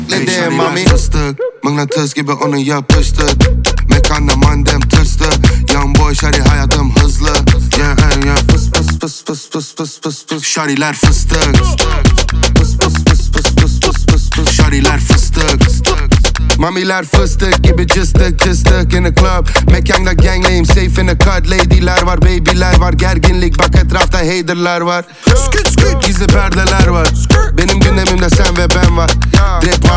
2023-07-13 Жанр: Поп музыка Длительность